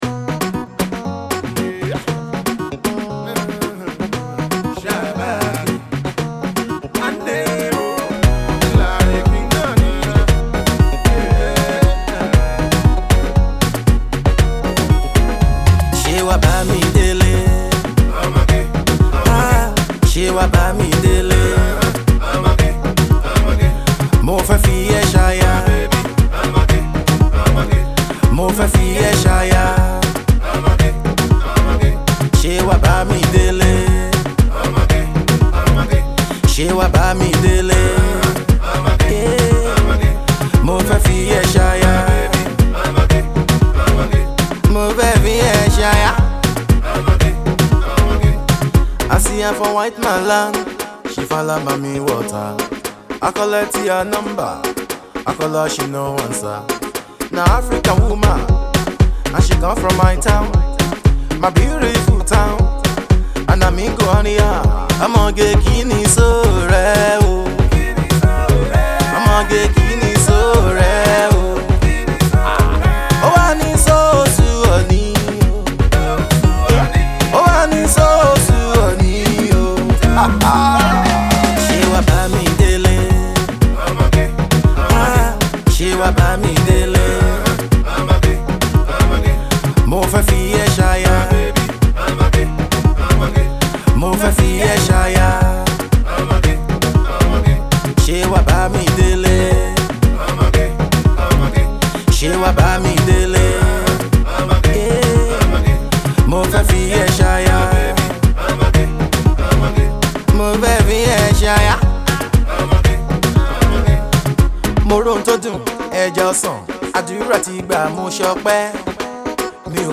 This song is a fusion of Afro beat and juju.